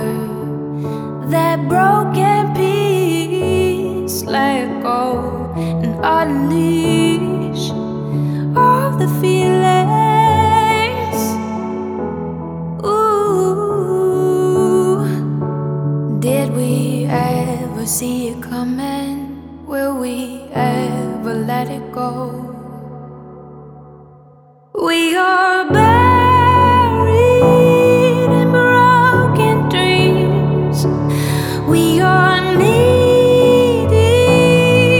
2017-06-02 Жанр: Альтернатива Длительность